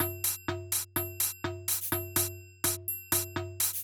Drumloop 125bpm 09-B.wav